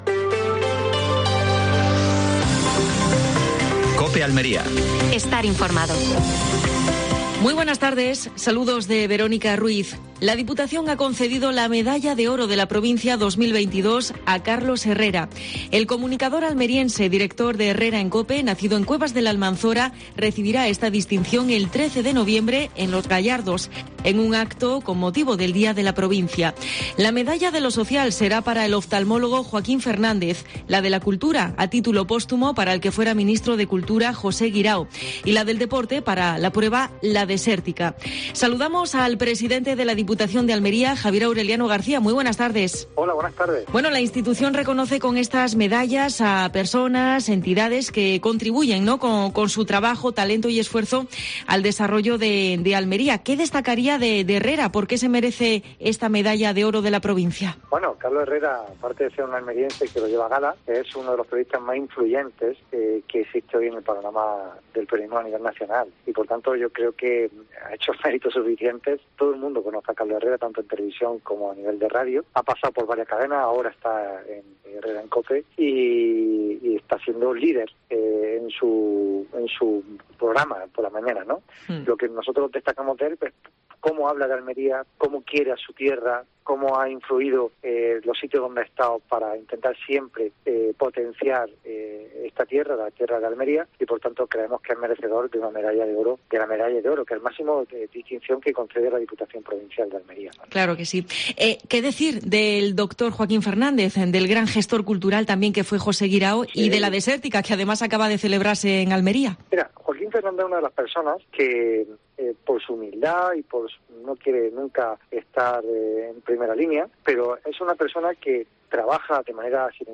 Última hora en Almería. Entrevista a Javier A. García (presidente de la Diputación Provincial de Almería).